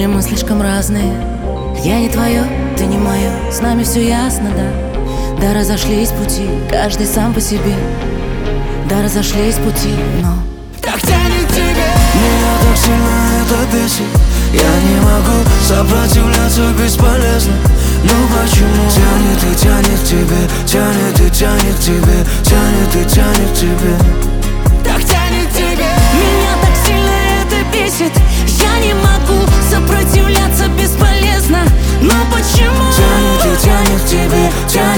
Жанр: Русская поп-музыка / Поп / Русский рок / Русские